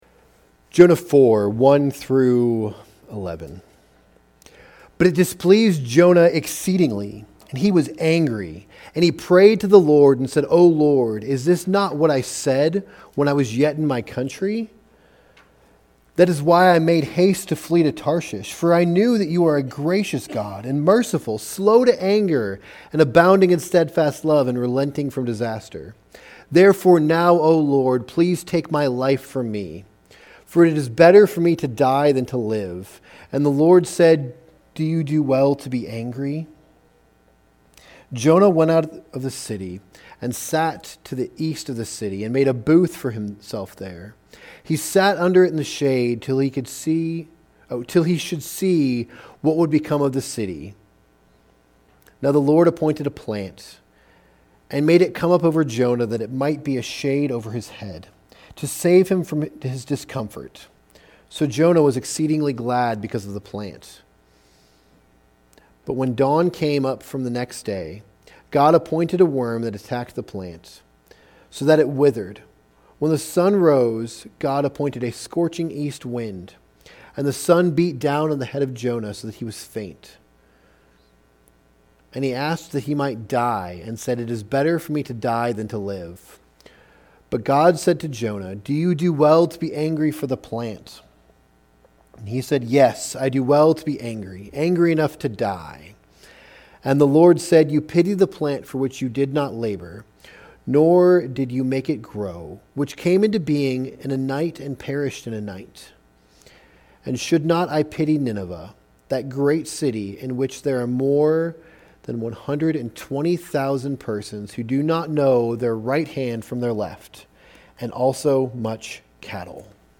Sermons | Maranatha Baptist Church